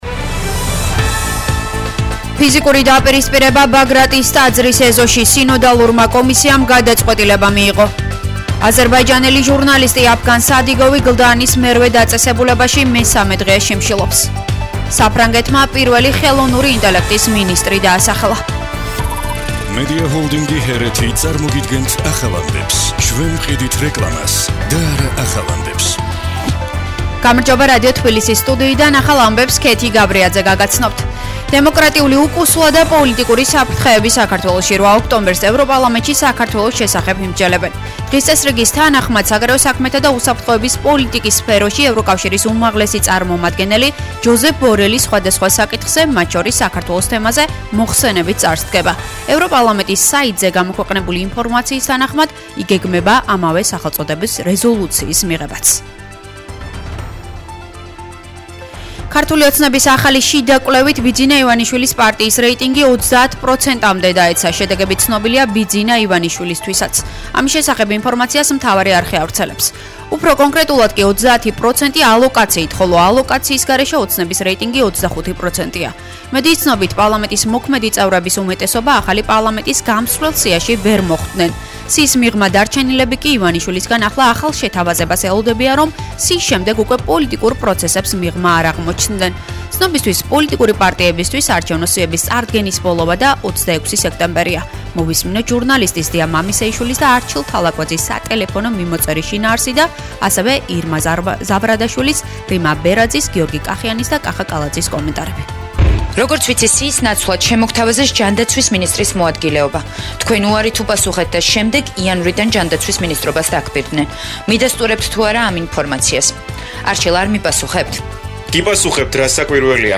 ახალი ამბები 17:00 საათზე